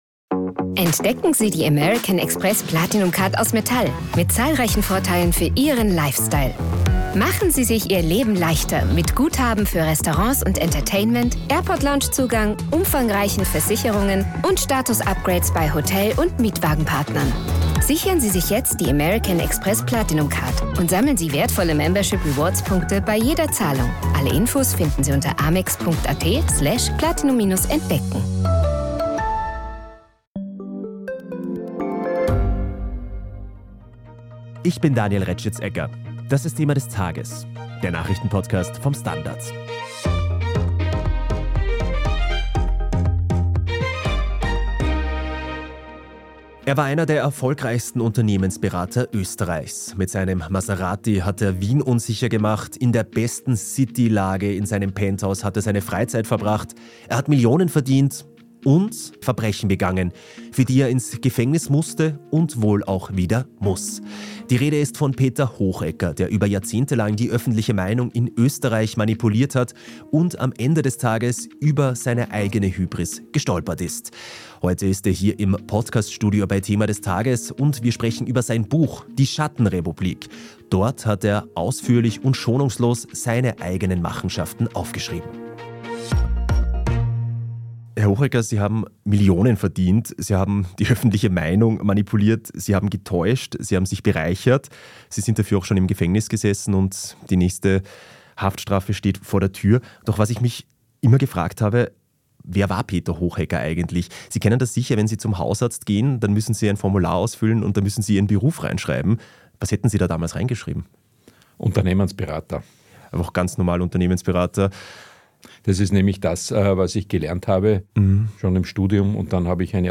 Kürzlich hat er ein Buch veröffentlicht, in dem er schonungslos über seine Machenschaften spricht. DER STANDARD hat ihn zum Interview getroffen.